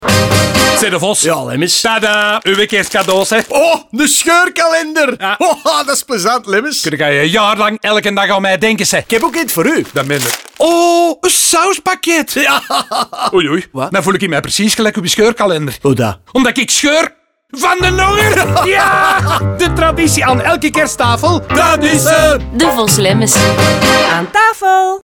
Het bekende duo zal de scheurkalender op hun typische manier aan de man proberen te brengen in een radiospot, en ook in de rest van de eindejaarscampagne komt het hebbeding nog regelmatig terug.
Devos & Lemmens_NL25s_Scheurkalender_Radio.mp3